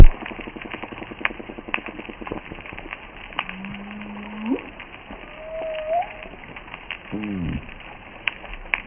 • Sounds of a coral reef
If you dove into the water of the U.S. Virgin Islands, this is what you would hear. Mostly, it’s a sound like bacon frying. The culprit is millions of microscopic snapping shrimp that live in the water column. But listen a little closer and you may hear a whale call.